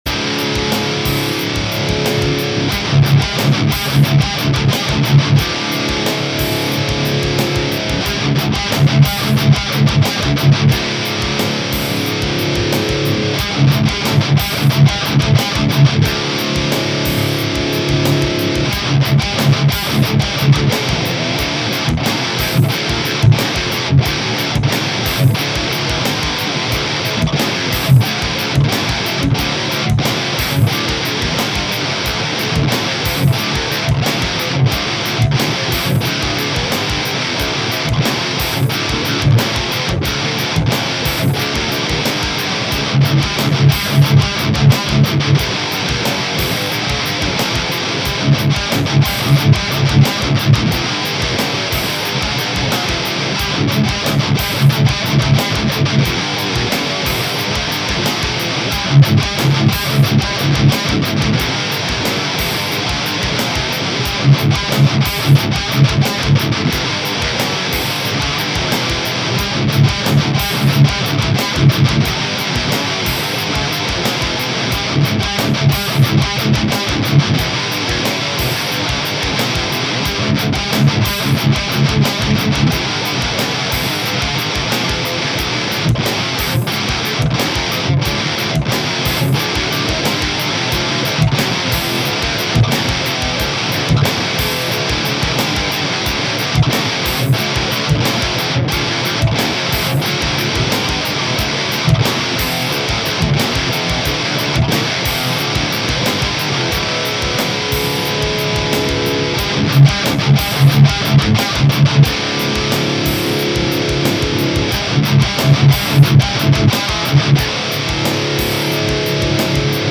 Fireball Gain Tone:
Good Tone!
Engl_Fireball_Gain.mp3